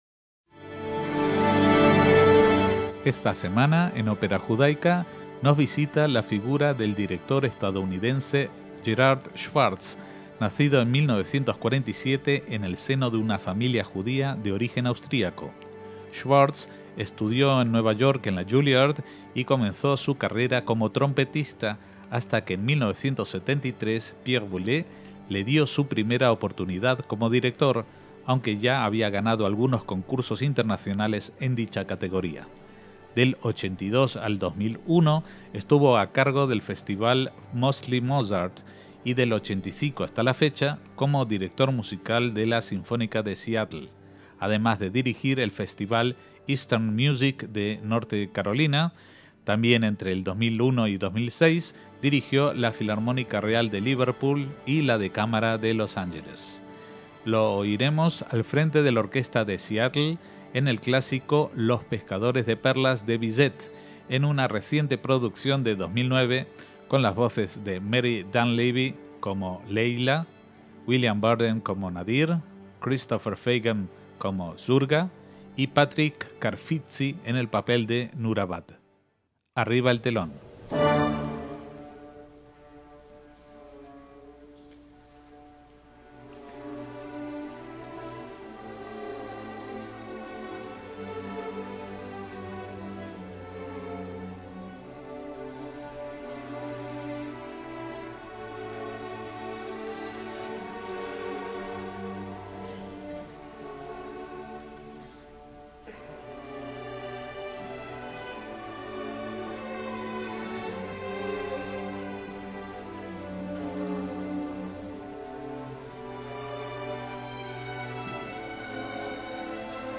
ÓPERA JUDAICA